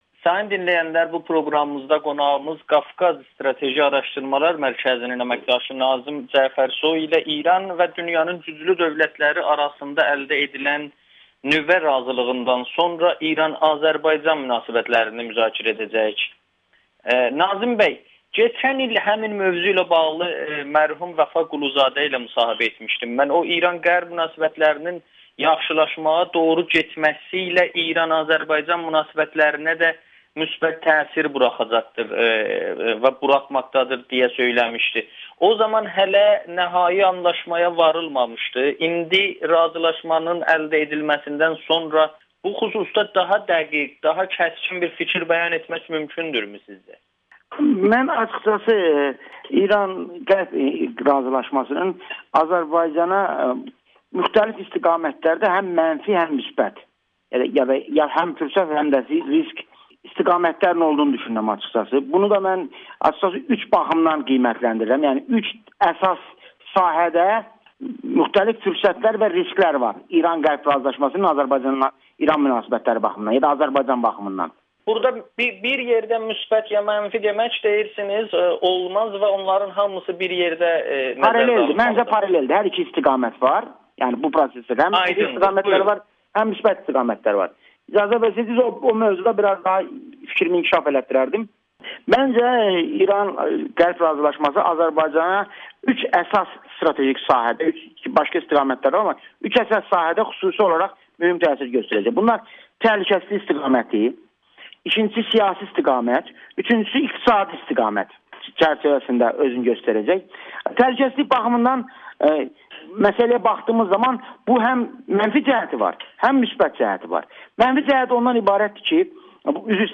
Nüvə razılığından sonra İran-Azərbaycan əlaqələri – fürsətlər və risklər [Audio-Müsahibə]